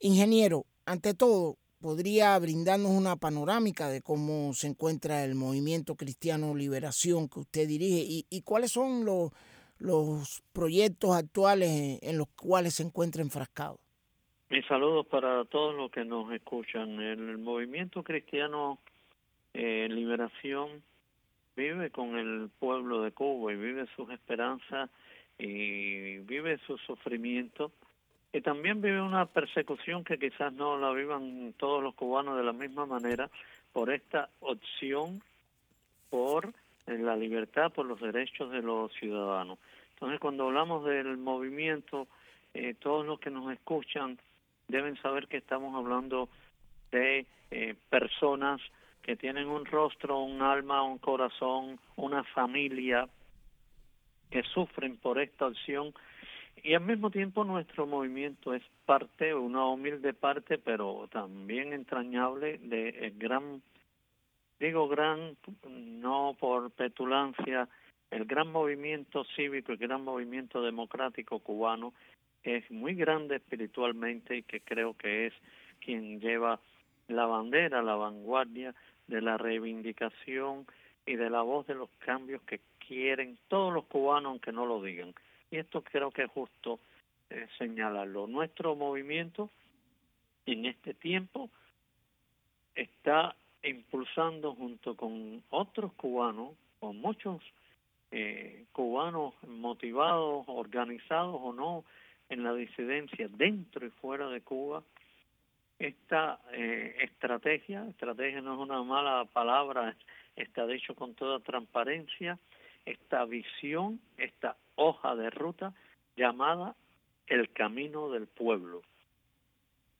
Fragmentos de la última entrevista de Oswaldo Payá con el programa "Cuba al día", de Radio Martí.